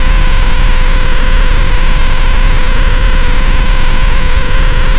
spaceEngineLarge_000.ogg